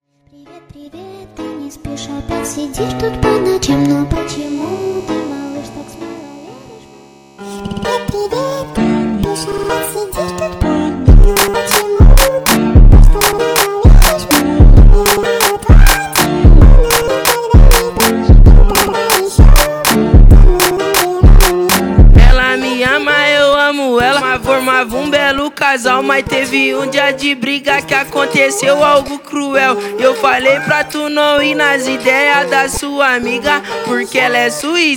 # Бразильская музыка